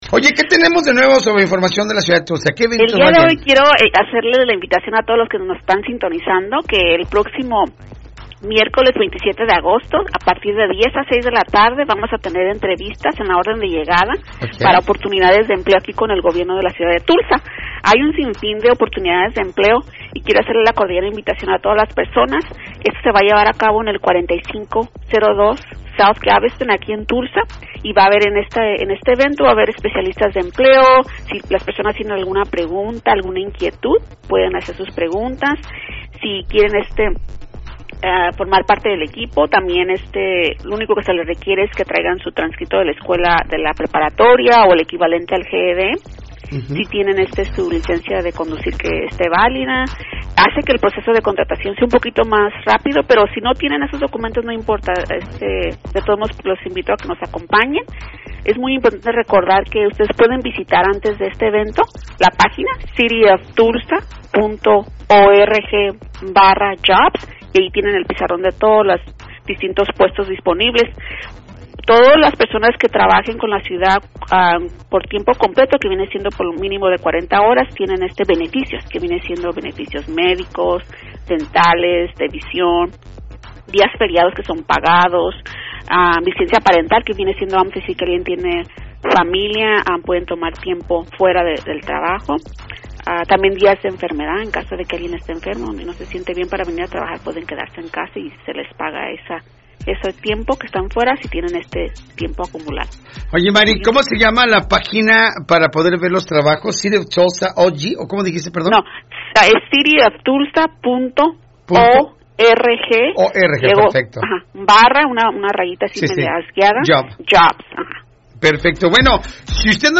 🎧 Escucha la entrevista completa en nuestro podcast y mantente informado sobre las oportunidades y actividades que la ciudad tiene para ti.